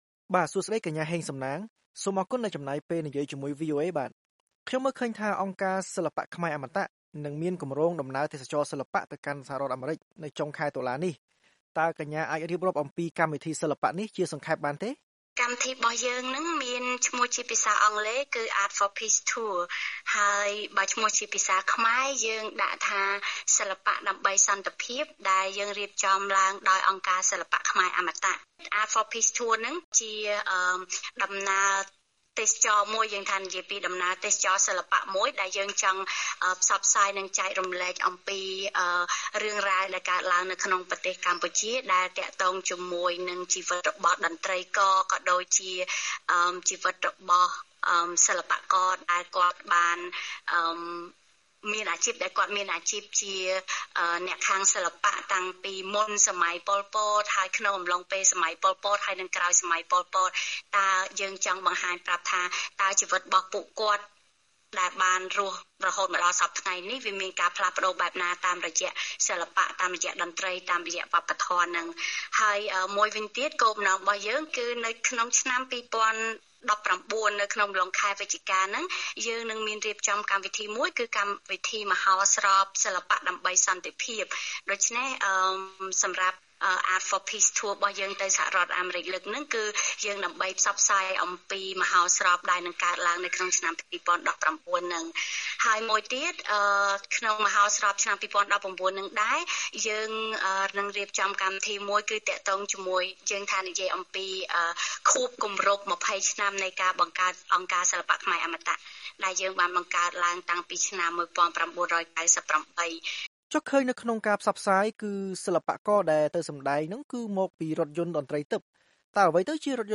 បទសម្ភាសន៍
តាមទូរស័ព្ទពីរដ្ឋធានីវ៉ាស៊ីនតោន